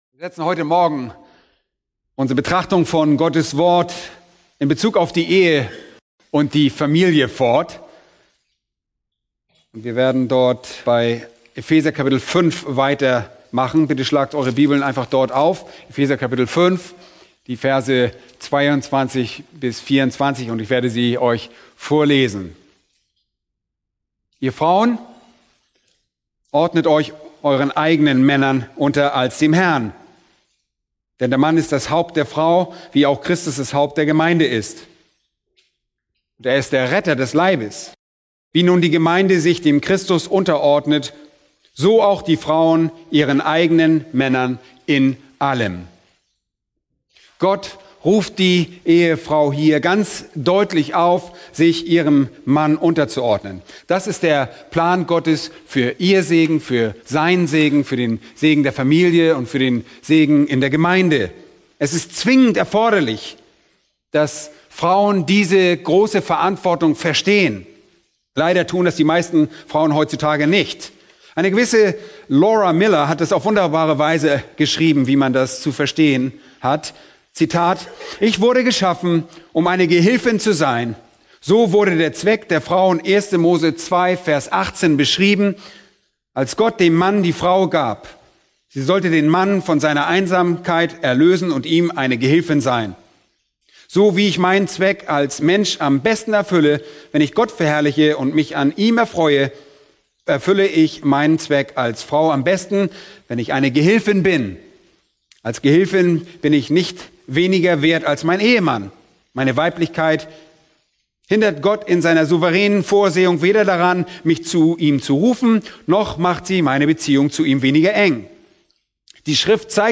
Eine predigt aus der serie "Die erfüllte Familie*." Epheser 5,22-24